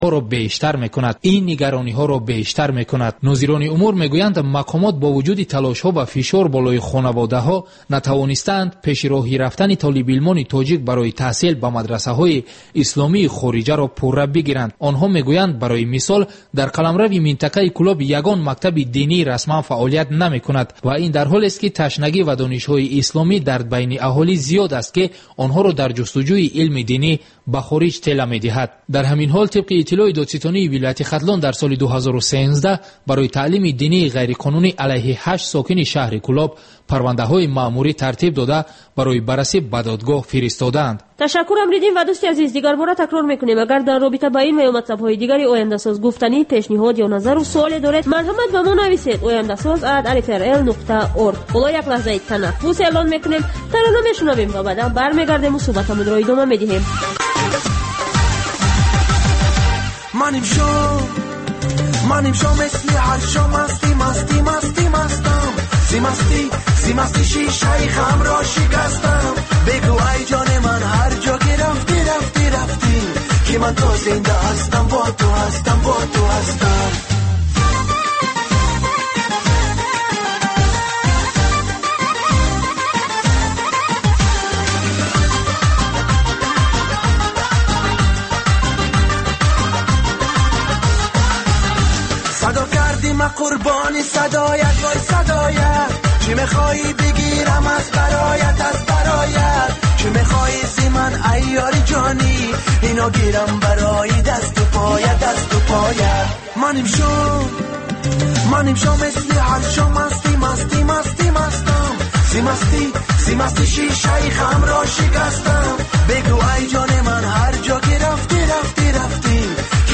Гузориш, мусоҳиба, сӯҳбатҳои мизи гирд дар бораи муносибати давлат ва дин.